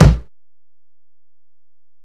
Kick (30).wav